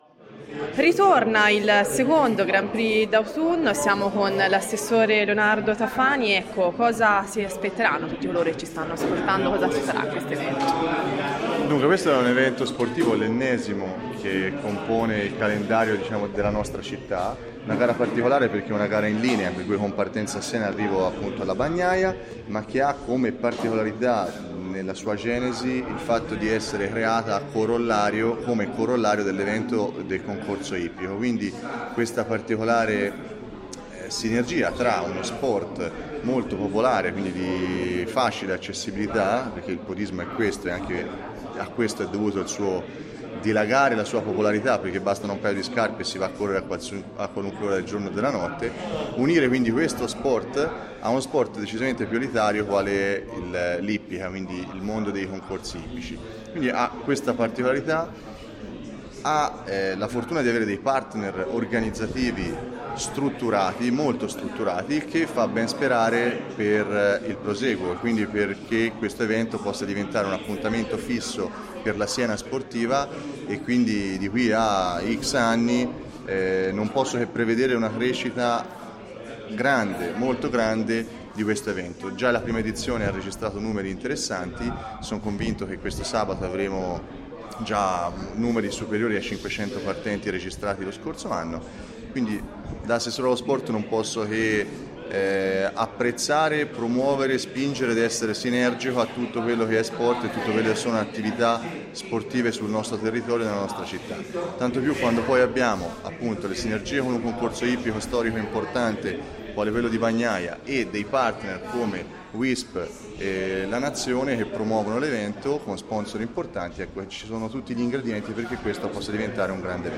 Ascolta l’intervista dell’assessore allo sport del comune di Siena Leonardo Tafani